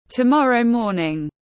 Sáng mai tiếng anh gọi là tomorrow morning, phiên âm tiếng anh đọc là /təˈmɒr.əʊ ˈmɔː.nɪŋ/
Tomorrow morning /təˈmɒr.əʊ ˈmɔː.nɪŋ/